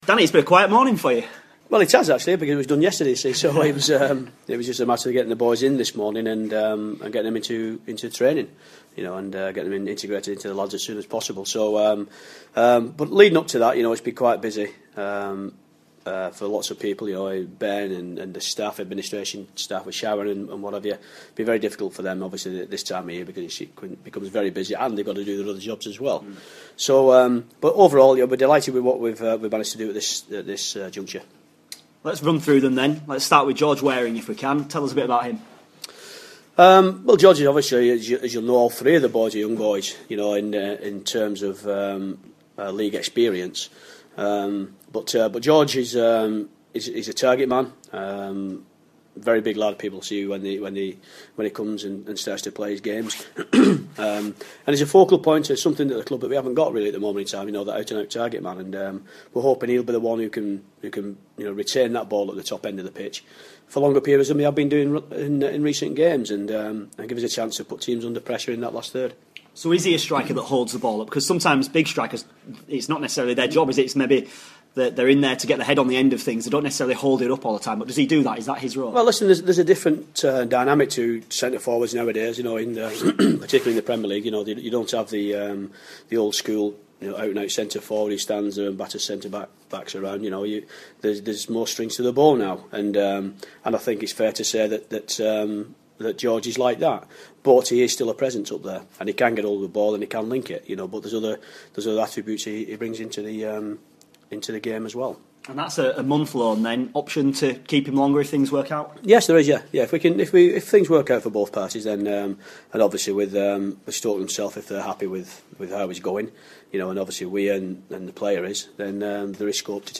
INTERVIEW: Barnsley manager Danny Wilson on his three new signings and the possibility of more to come.